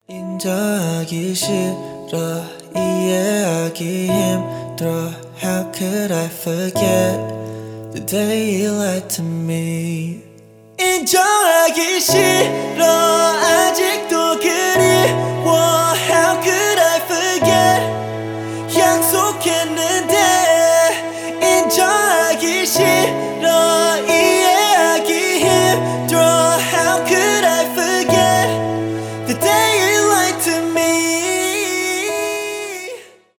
романтические
поп